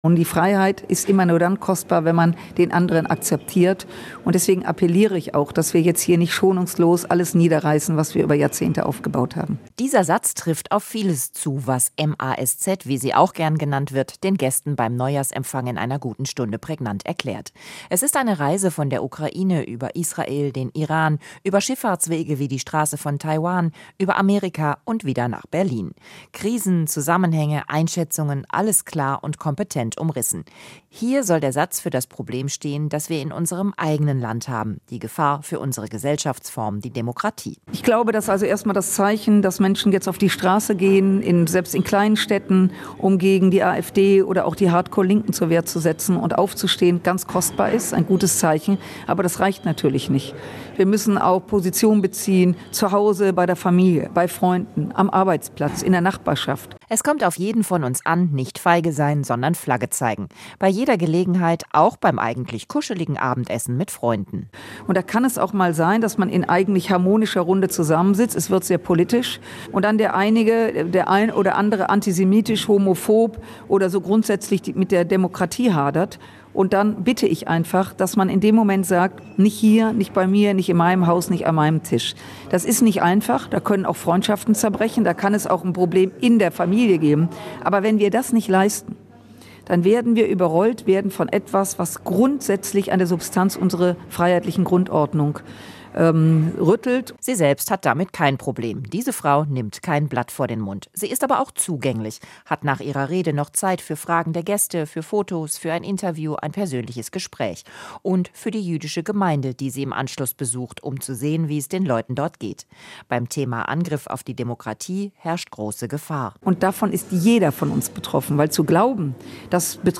Da ist der Hagener FDP ein echter Coup gelungen: Zum Neujahrsempfang am Samstag kam prominenter Besuch ins Stadthallenrestaurant. Marie-Agnes Strack-Zimmermann ist Vorsitzende des Verteidigungsausschusses, Europawahl-Spitzenkandidatin und eine der beliebtesten Politikerinnen Deutschlands.